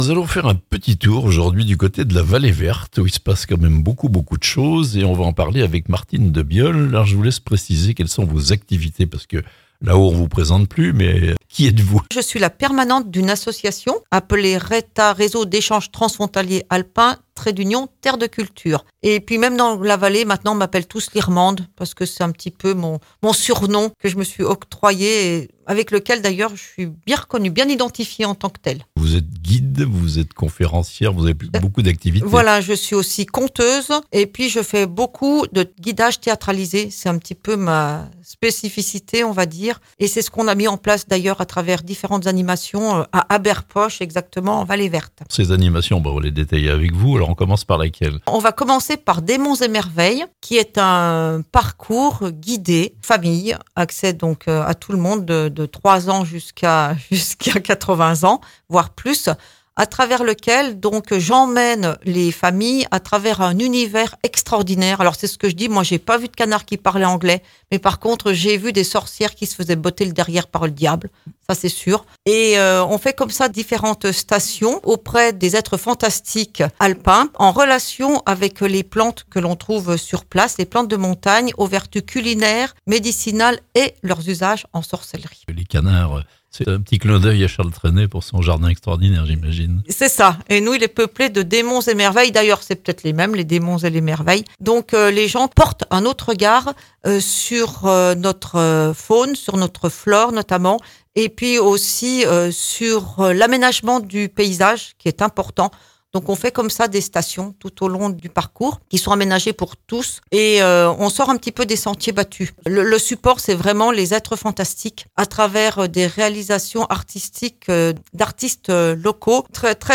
Les esprits vont se réveiller cet été en Vallée Verte (interview)